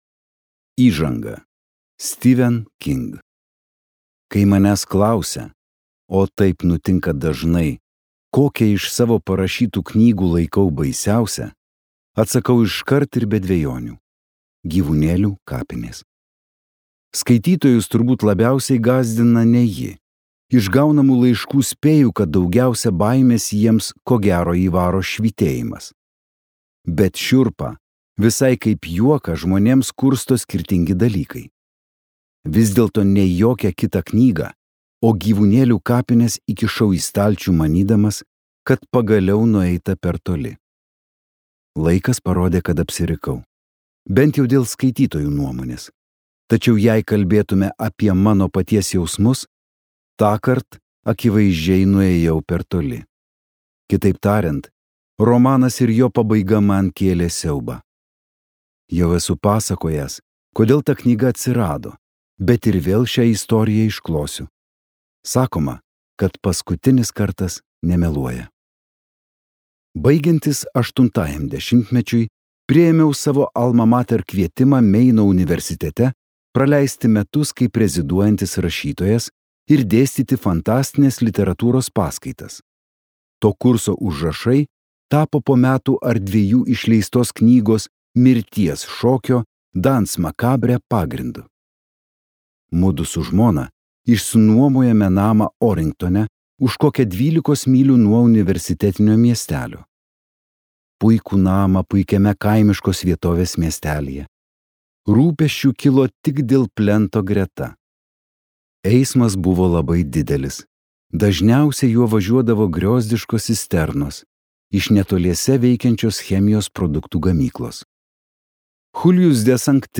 Givunėliu kapinės | Audioknygos | baltos lankos